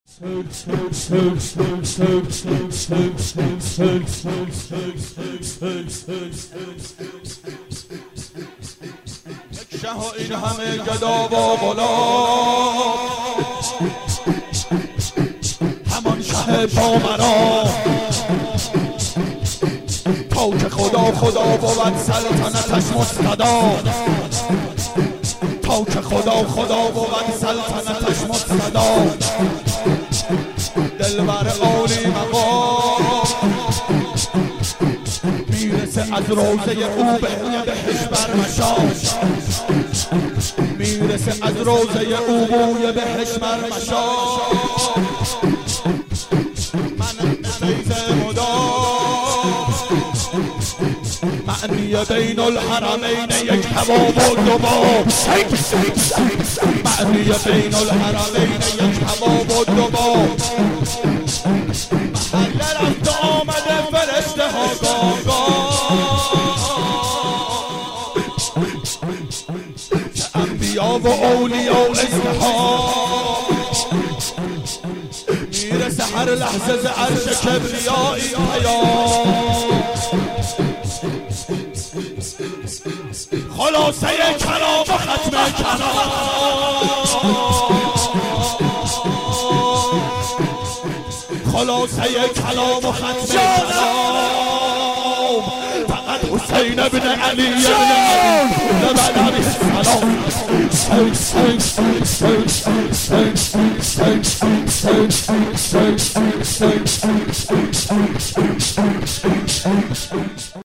• شام غریبان حضرت زهرا سلام الله علیها 89 هیئت محبان امام حسین علیه السلام شهر اژیه